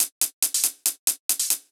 UHH_ElectroHatA_140-02.wav